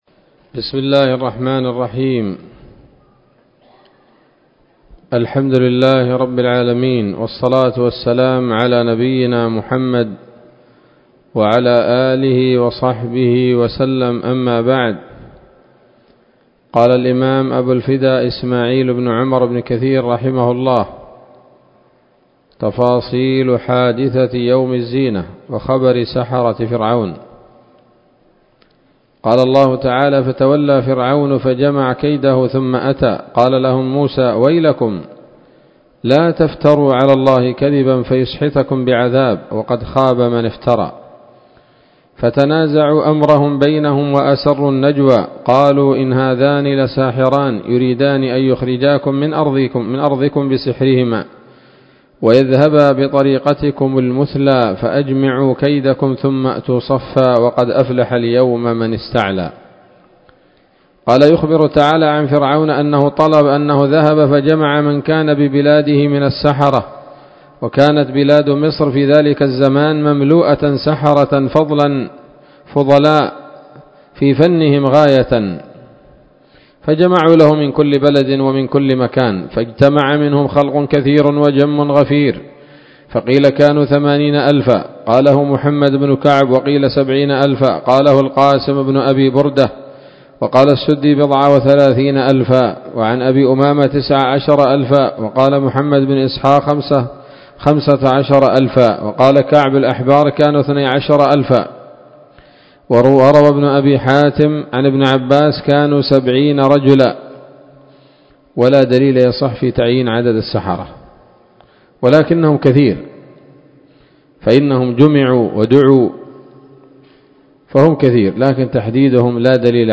الدرس الثامن والثمانون من قصص الأنبياء لابن كثير رحمه الله تعالى